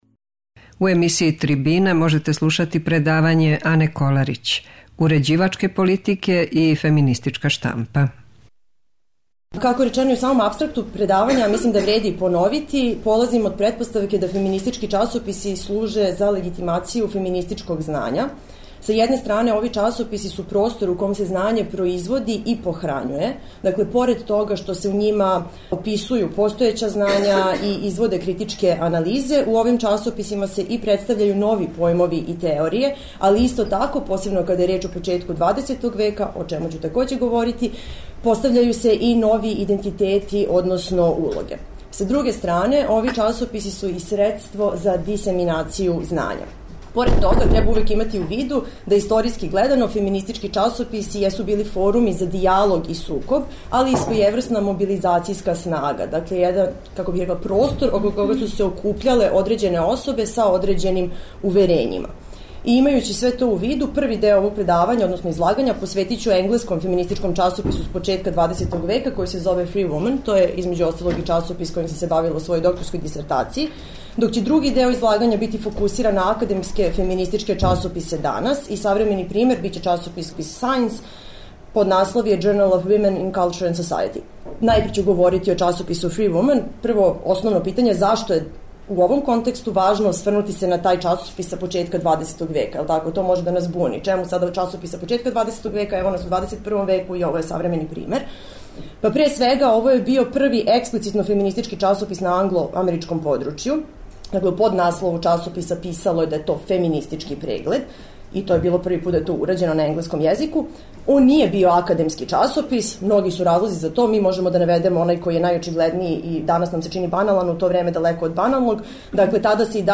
Предавање је снимљено 1. марта у Институту за књижевност и уметност.